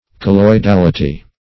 Colloidality \Col`loi*dal"i*ty\, n. The state or quality of being colloidal.